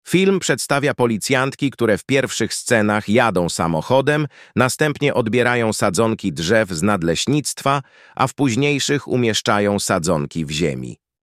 Nagranie audio Audiodeskrypcja filmu.